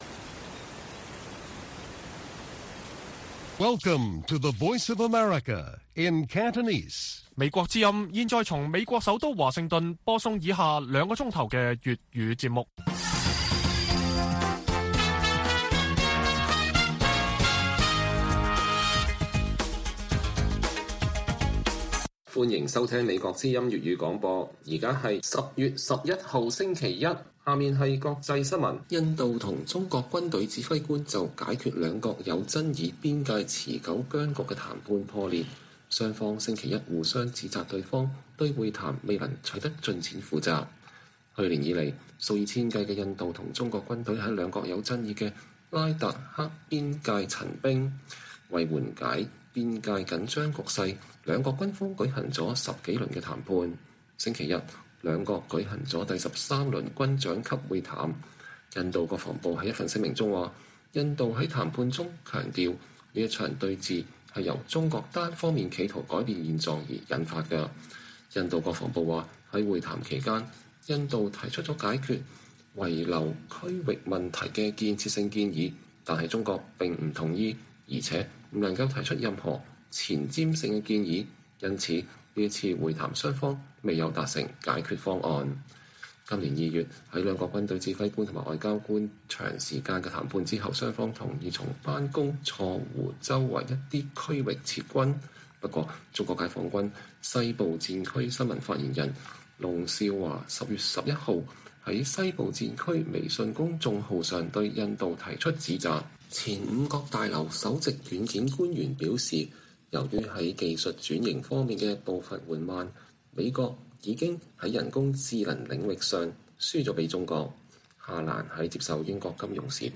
粵語新聞 晚上9-10點: 中印兩軍相互指責對方對談判破裂負責